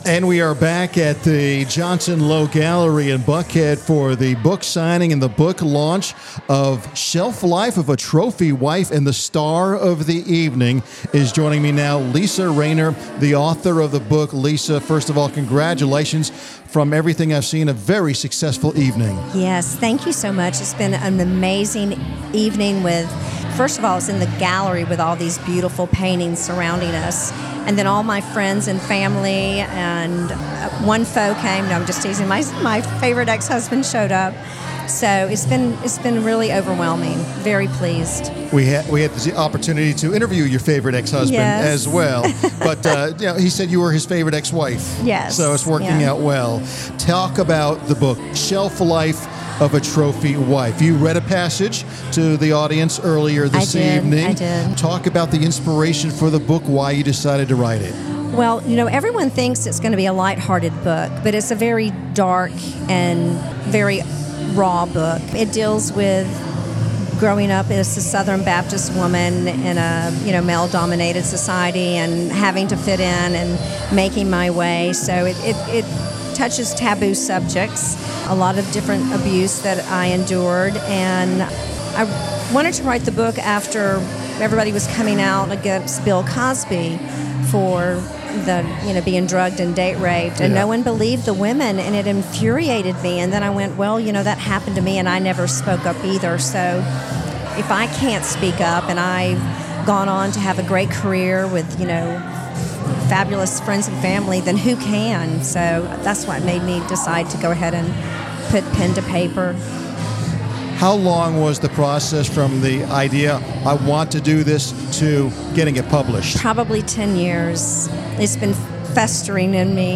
Business RadioX was on site to interview the author and distinguished guests attending the event, courtesy of Riptide, LLC.